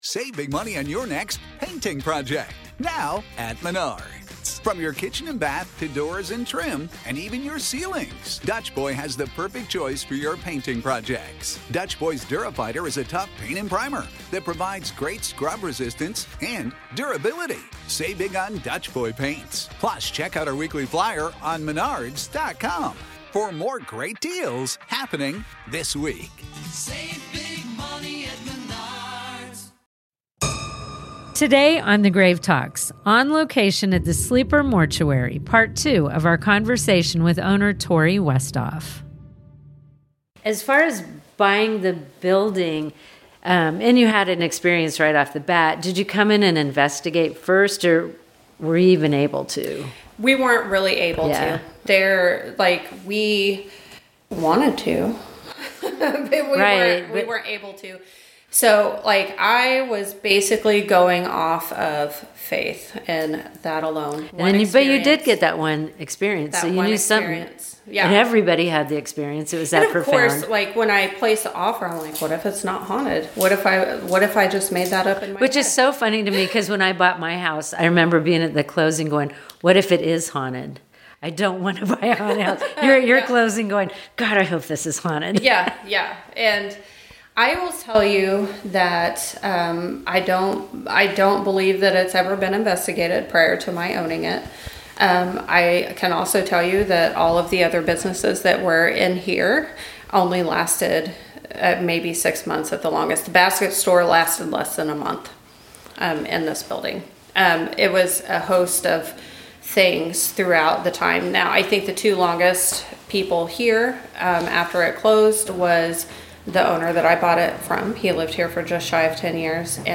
Some places don’t just hold memories—they refuse to be forgotten. I recently took a drive to Iola, Kansas, to visit the Sleeper Mortuary, a building that stands frozen in time, untouched since its days as a funeral home.
This is Part Two of our conversation.